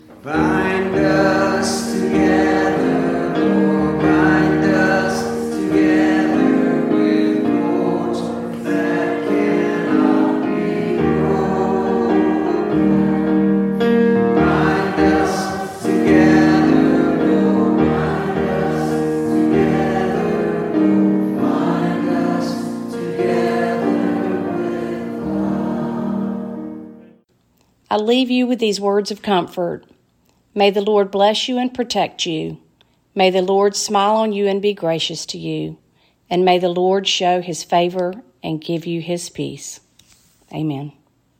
Bethel 10/24/21 Service
09-Closing_Chorus_and_Benediction.mp3